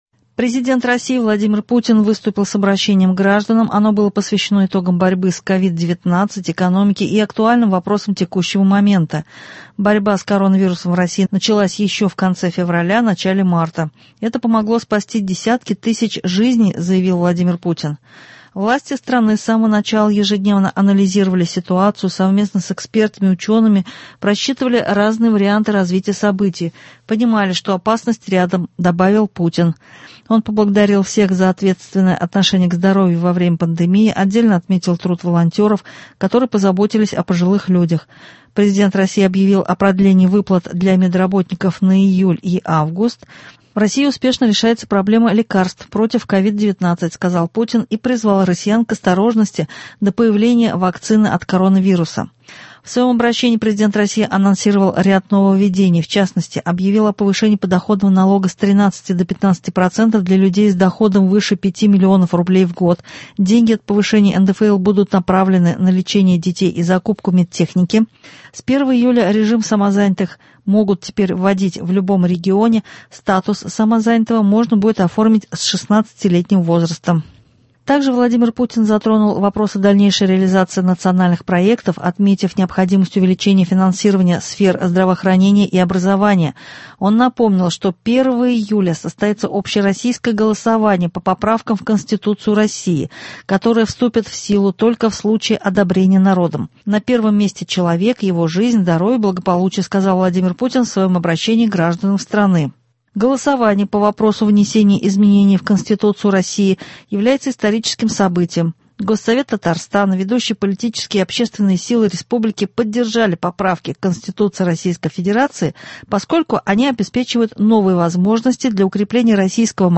Новости. 24 июня. | Вести Татарстан